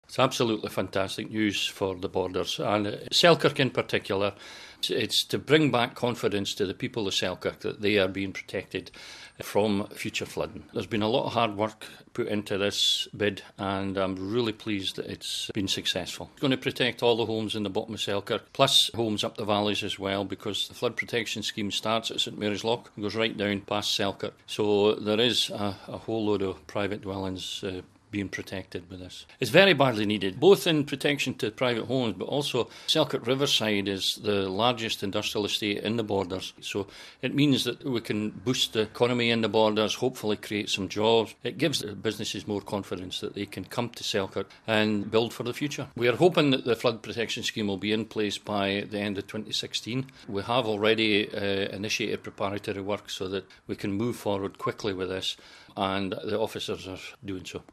Scottish Borders Council's Executive Member of Roads and Infrastructure has been speaking to Radio Borders News after the Scottish Government approved funding for the scheme. The project is due to be completed in December 2016.